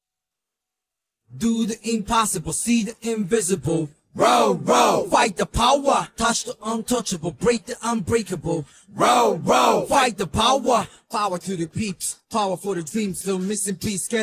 row_vocals.mp3